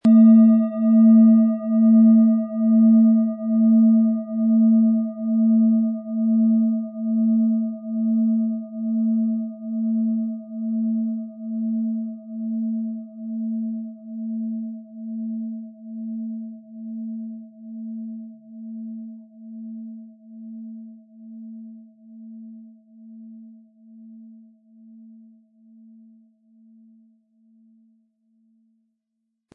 Planetenton 1
Thetawelle
Planetenschale® antik Meditationen mit beruhigten Gedanken & Gedankenruhe mit Thetawellen, Ø 17,6 cm inkl. Klöppel